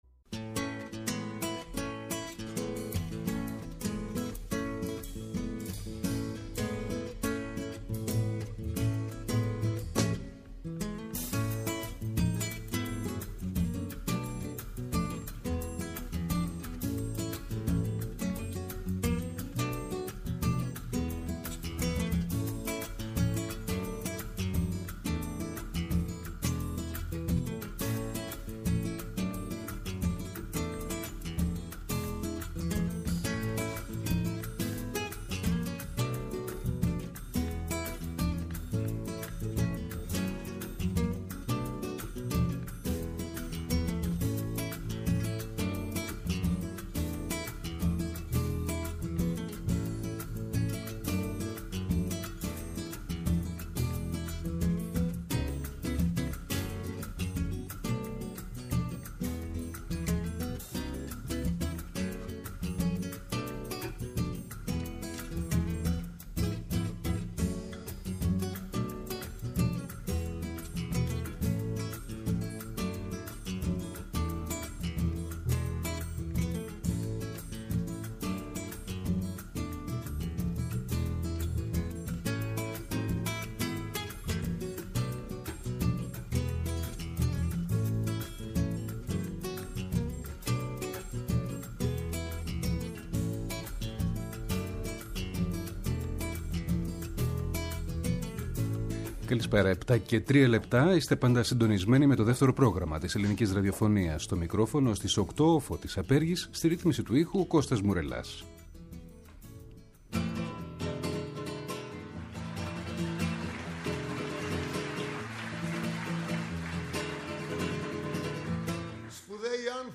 ΔΕΥΤΕΡΟ ΠΡΟΓΡΑΜΜΑ Η Καταλληλη Ωρα Live στο Studio Μουσική Συνεντεύξεις